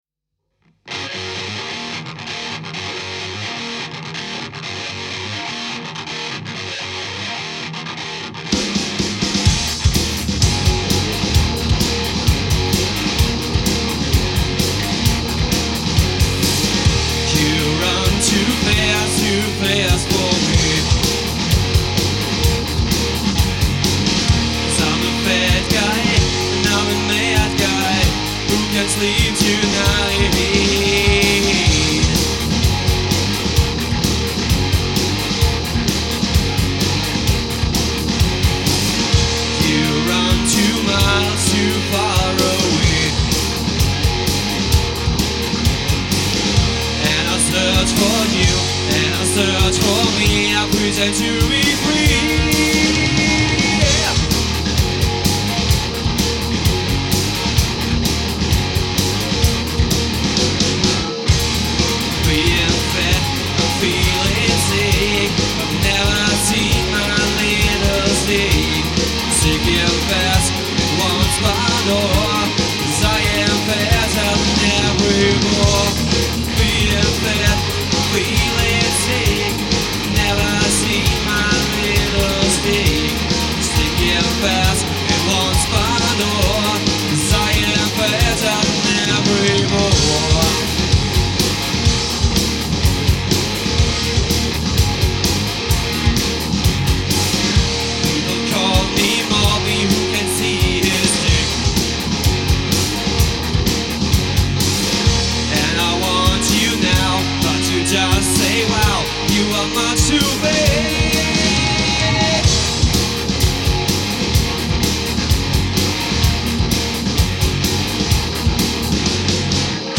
Vocals/ Guitar
Drums
Bass/ Vocals